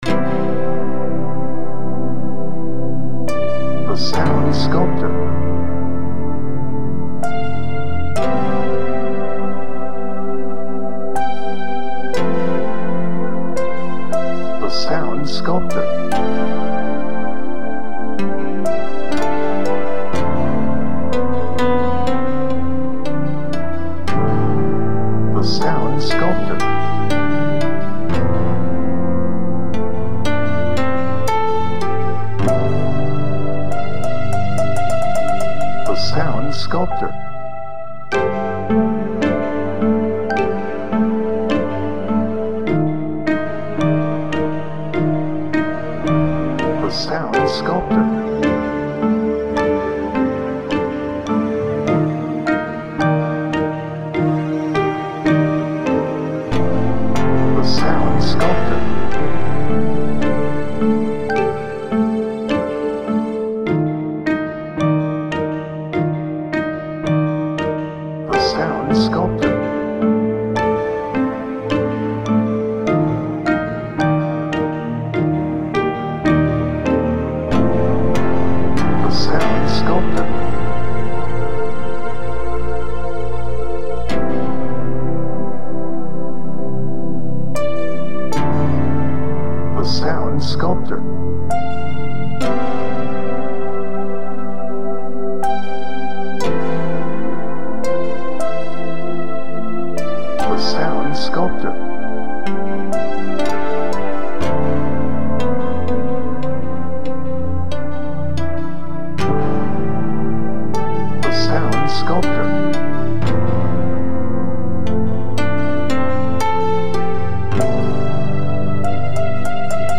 Introspective
Peaceful
Positive
Reflective